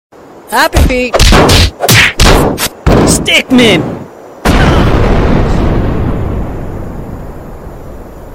stickman-punch.mp3